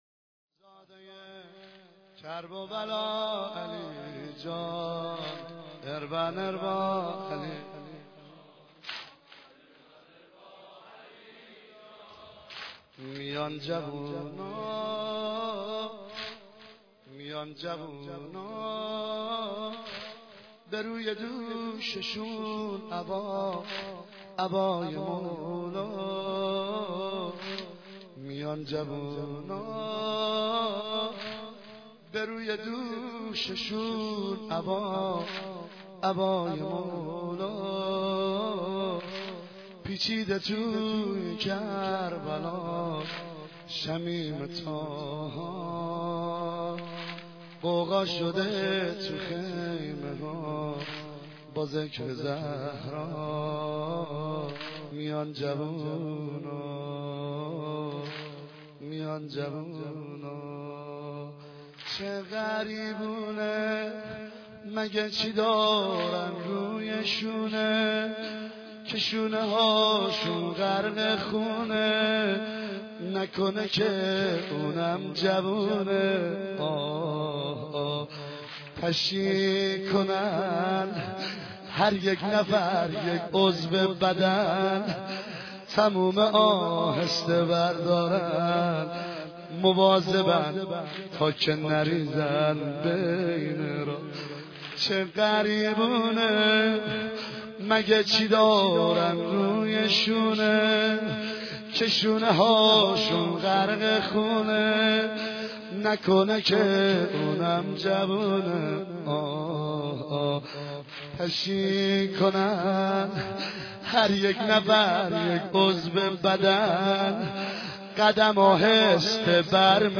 سنگین مداح اهل بیت استاد